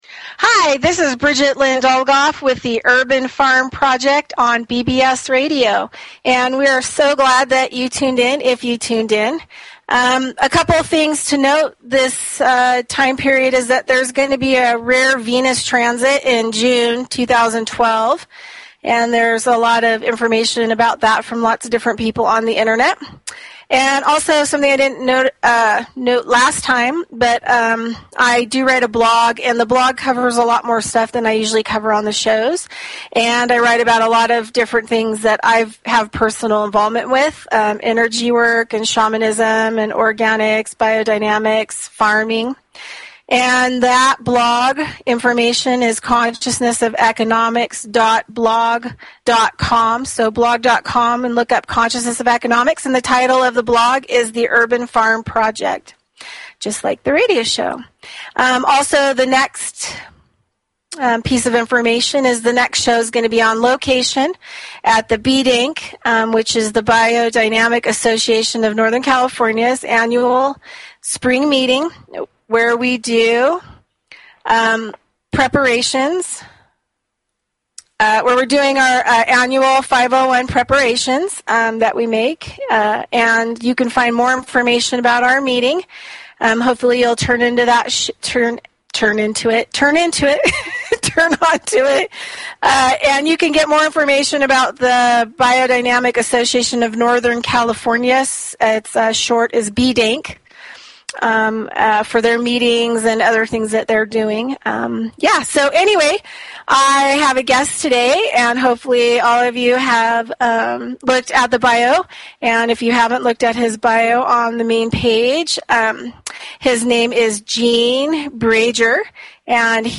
Talk Show Episode, Audio Podcast, The_Urban_Farm_Project and Courtesy of BBS Radio on , show guests , about , categorized as